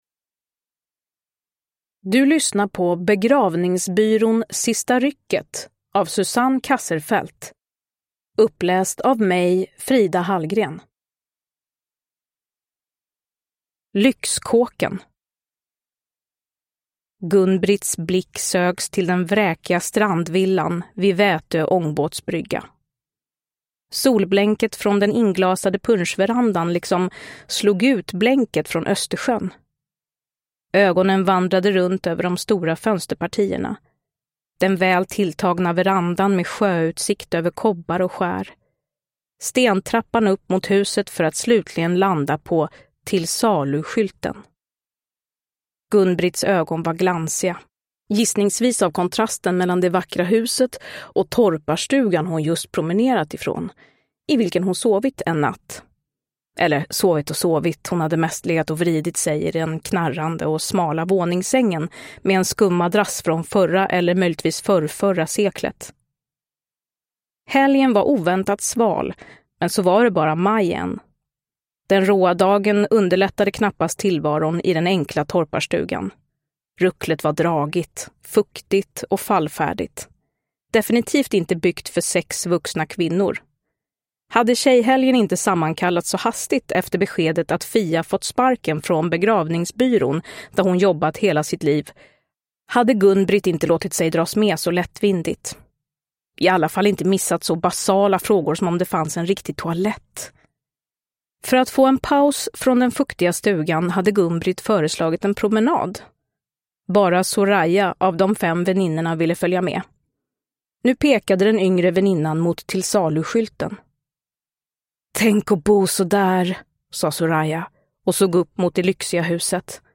Uppläsare: Frida Hallgren
Ljudbok